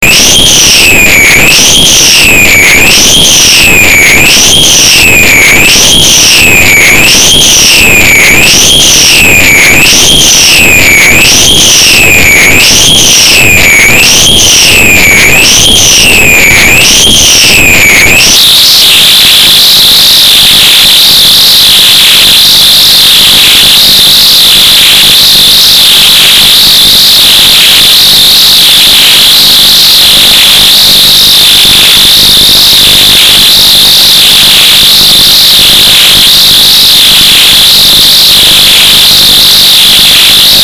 Ultrasound into ultrasound interference using an electronic ultrasound mosquito repellent.
ultrasound-into-ultrasound-interference.mp3